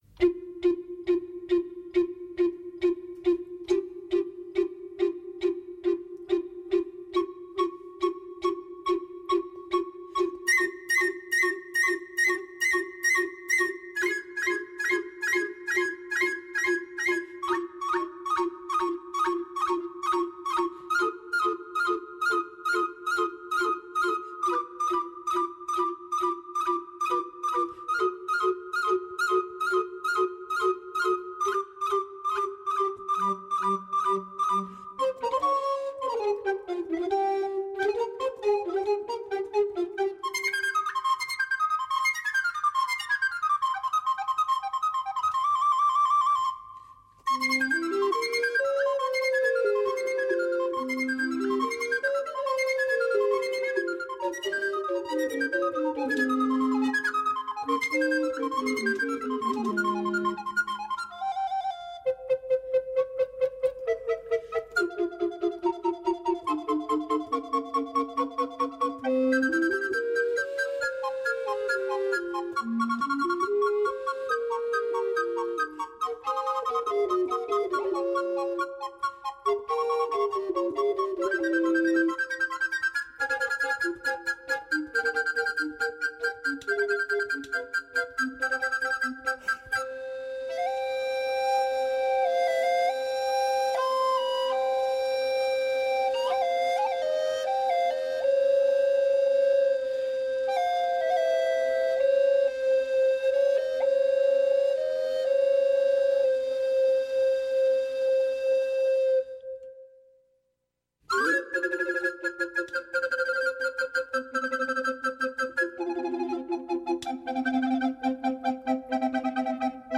27 març Entrevista a Gente Radio